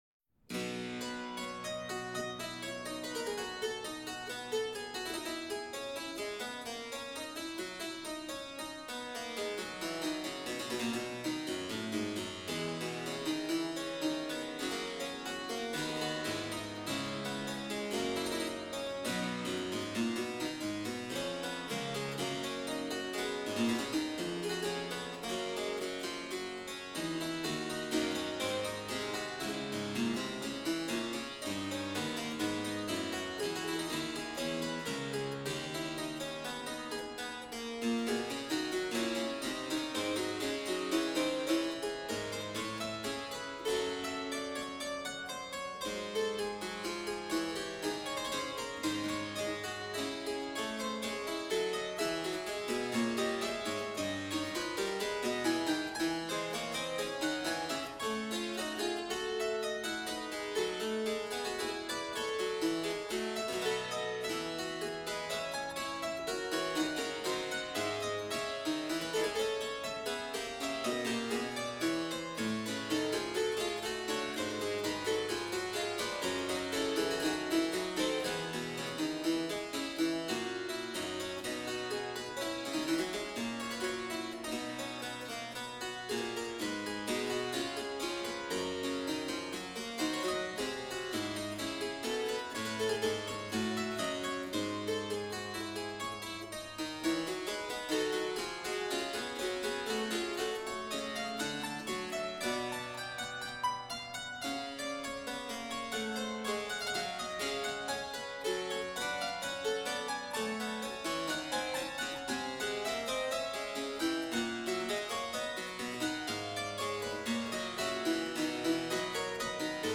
Chords were rolled (played one note after the other quickly, instead of all together) to simulate more volume.
This piece has two distinctive parts.
During the prelude, listen for the melody to go in multiple directions at once, called counterpoint.
This fugue is played by the harpsichord and because there’s little dynamic range, it can be a little challenging to pick out the higher and lower “voices” of each rendition of the main melody.
If, upon hitting the play button below, you are experiencing your first brush with all that is the awful harpsichord…my deepest apologies.